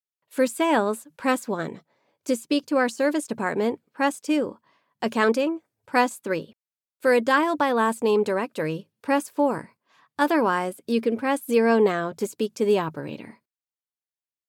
Female
American English (Native)
My voice sits in the millennial / Gen Z range – from early 20s to 40s, with a General American accent.
Instructional eLearning Dem....mp3
Microphone: Sennheiser MKH416